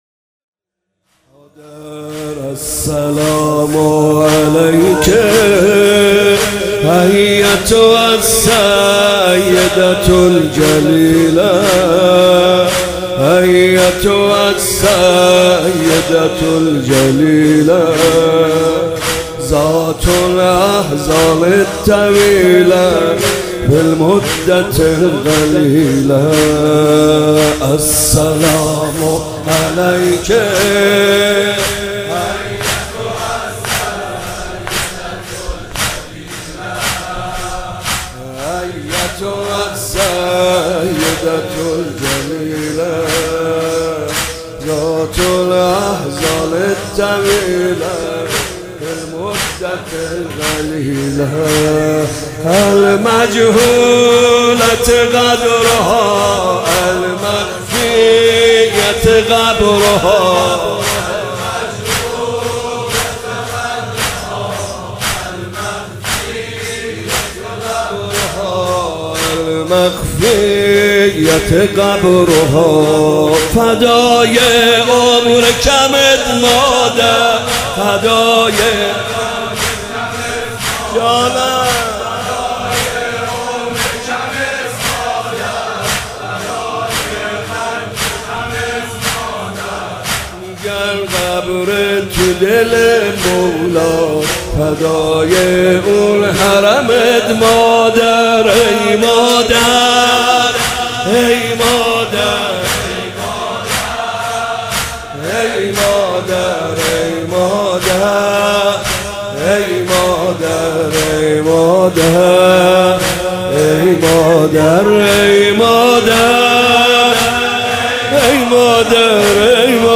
فاطمیه 96 - ا اسفند - زمینه - السلام علیک ایتها السیده الجلیله